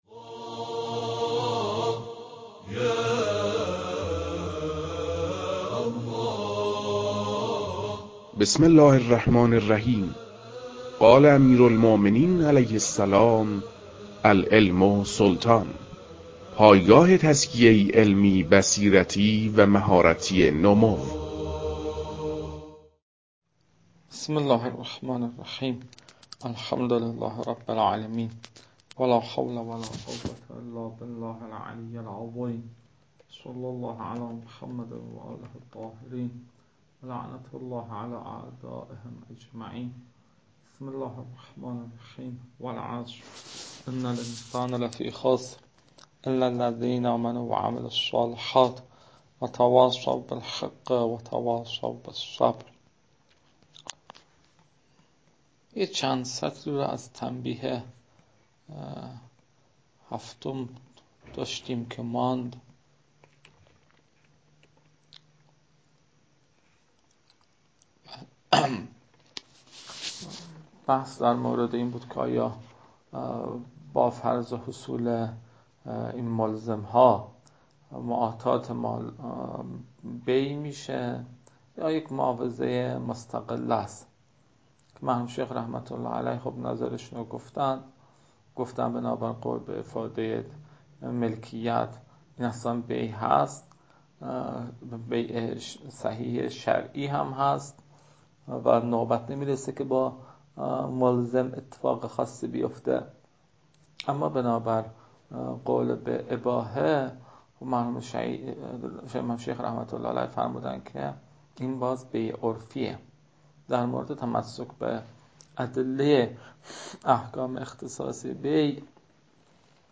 فایل های مربوط به تدریس مباحث تنبیهات معاطات از كتاب المكاسب متعلق به شیخ اعظم انصاری رحمه الله